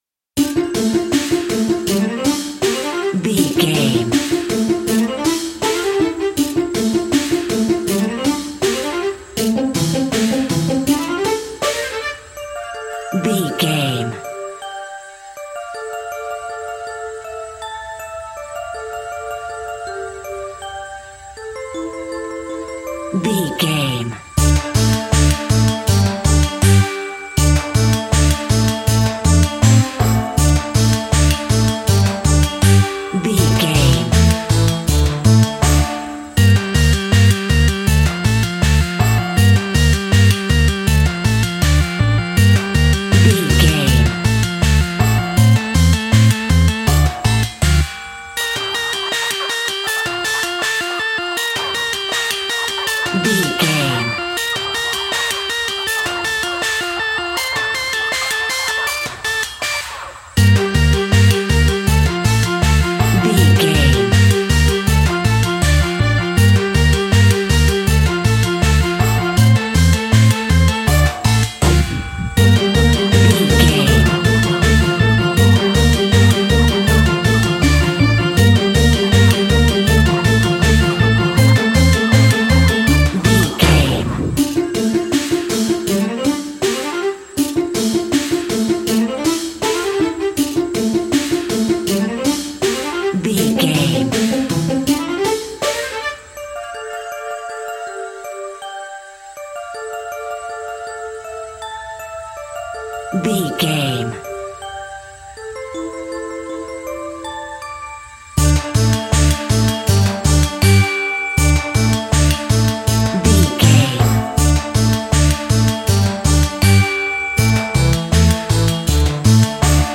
royalty free music
Aeolian/Minor
ominous
eerie
synthesiser
drum machine
spooky
horror music